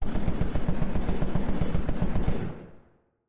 Commotion16.ogg